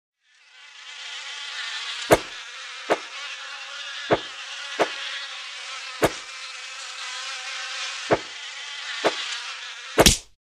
Звуки насекомых
Жужжащая муха летает, машут мухобойкой, убивают муху